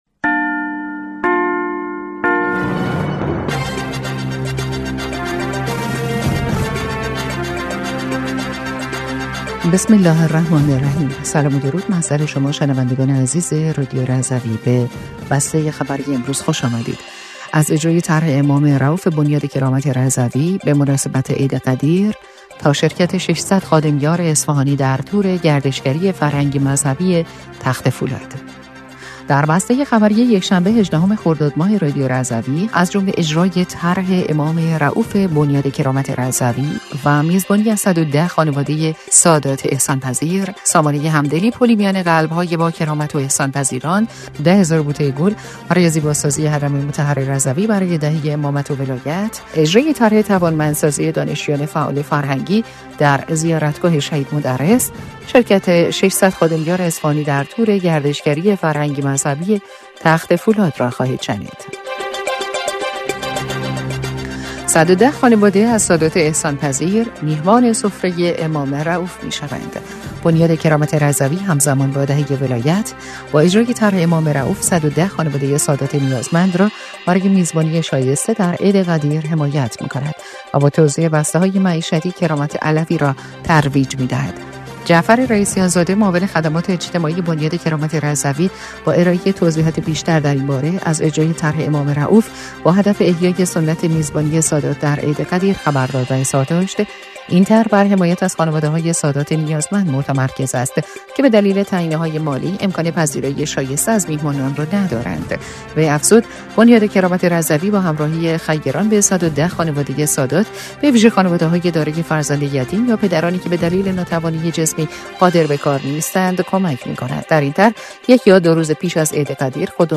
بسته خبری